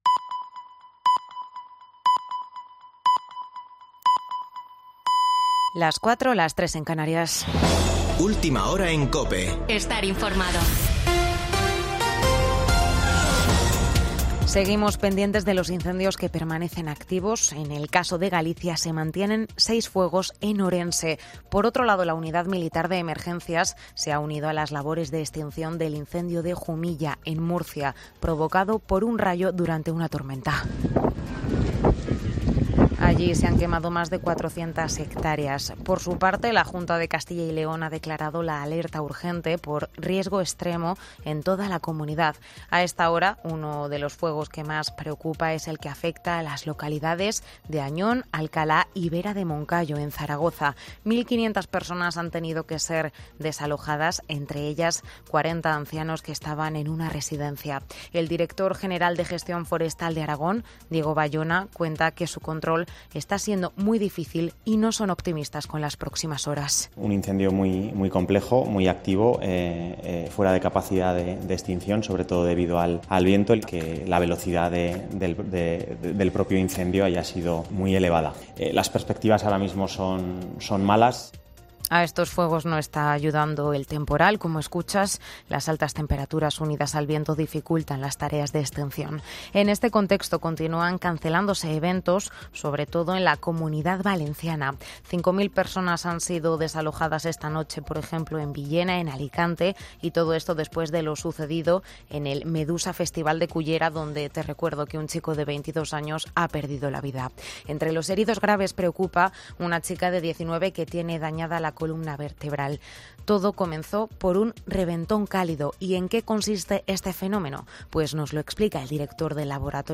Boletín de noticias de COPE del 14 de agosto de 2022 a las 04.00 horas